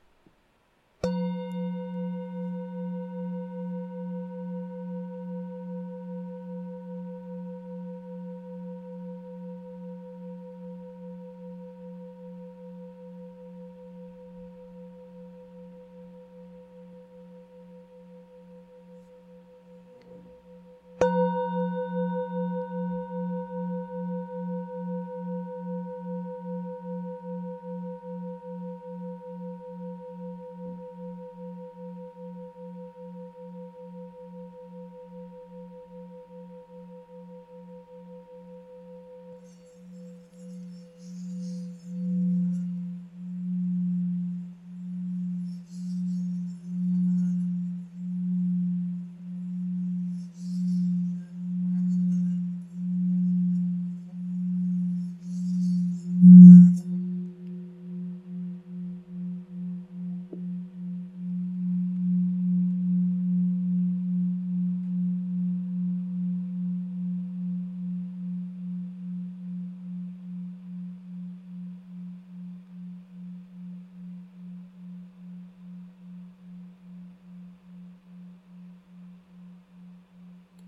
Campana tibetana contemporanea , creata con l’antica tradizione tibetana in lega dei 7 metalli.
Nota Armonica LA(A) #5 959 HZ
Nota di fondo FA(F) 3 178 HZ
Campana Tibetana Nota FA(F) 3 178 HZ